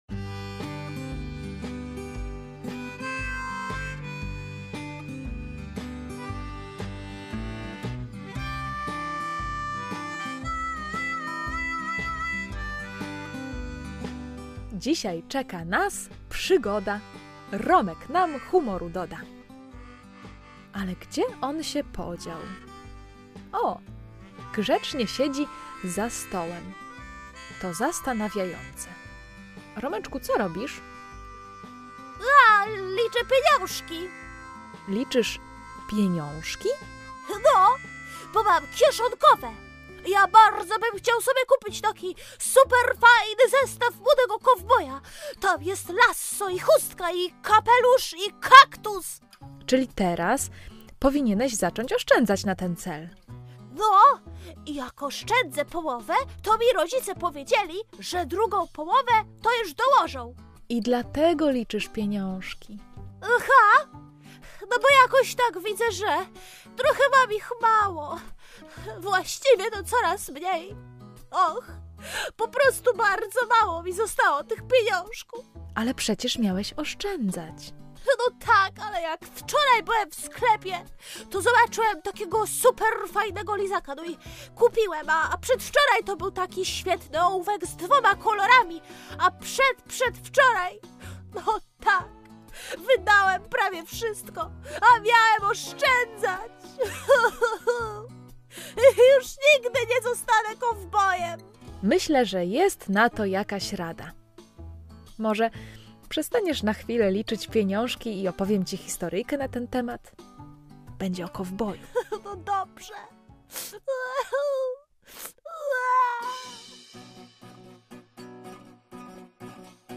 Romeczek OSZCZĘDZA! [AUDYCJA DLA DZIECI]